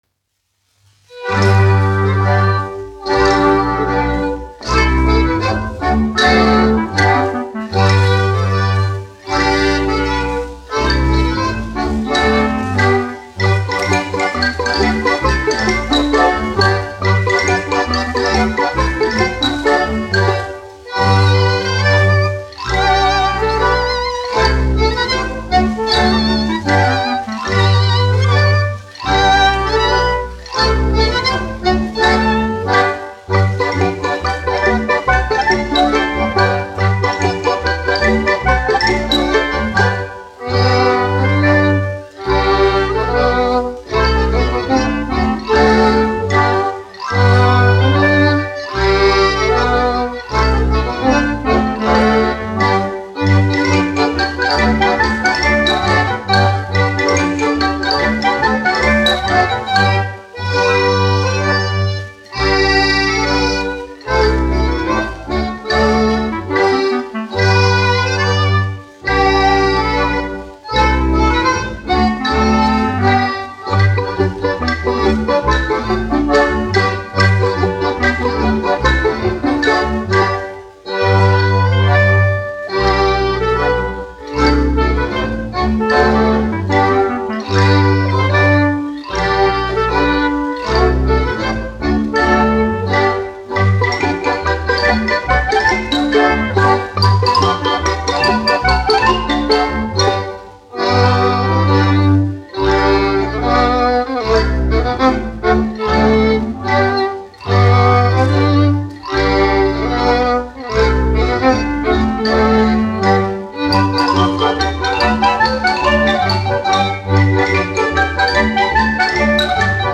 Tūdaliņ tagadiņ : tautas deja
Alfrēda Vintera Jautrā kapela (mūzikas grupa), izpildītājs
1 skpl. : analogs, 78 apgr/min, mono ; 25 cm
Latviešu tautas dejas